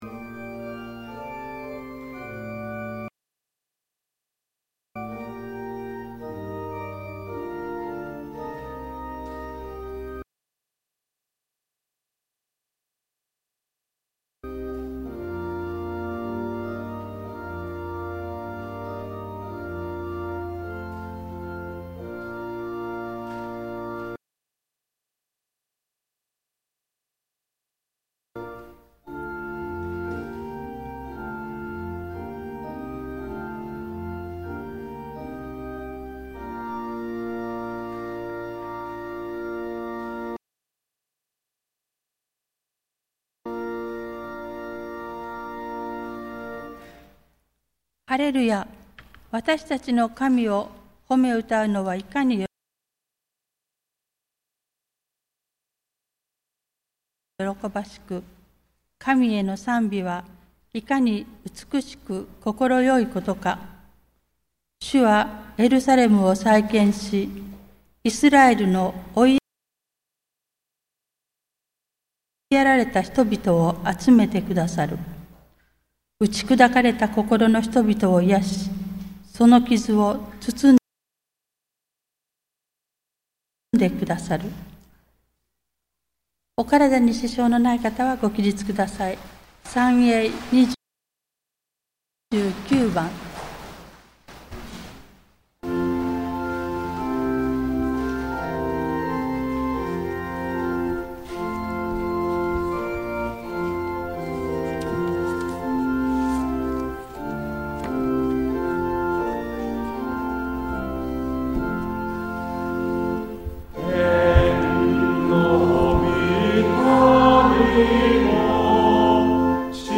2024年4月21日 日曜礼拝（音声）